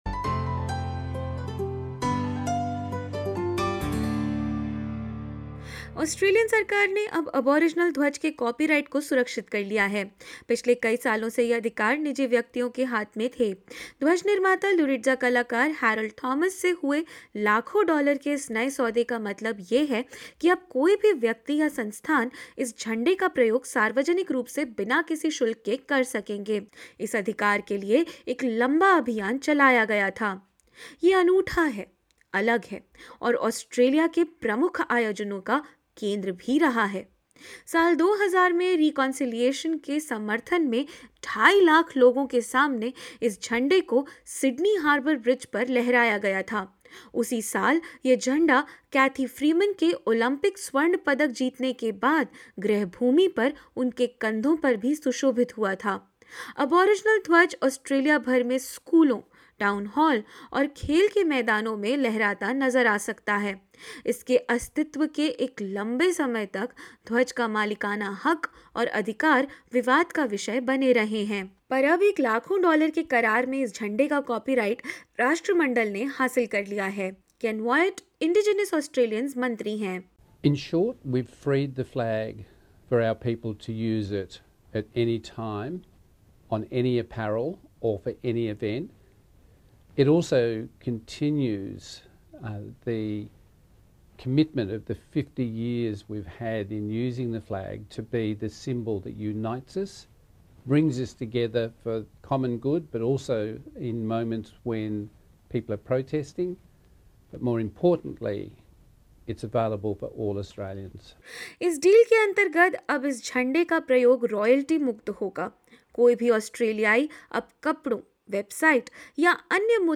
हालांकि कॉपीराइट के इस सौदे में कुछ सवाल हैं जिनका जवाब साफ़ नहीं है। आज की इस रिपोर्ट में जानेंगे इसी ऐतिहासिक अभियान के बारे में, और उन सवालों के बारे में भी जो इस नए सौदे के इर्द-गिर्द उठ रहे हैं।